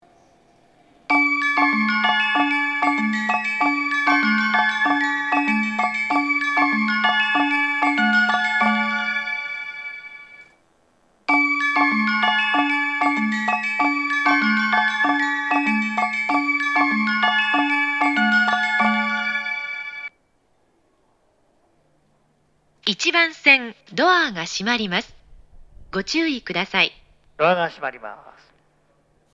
発車メロディー
1.9コーラスです!採時駅なのでフルコーラス鳴りやすいです。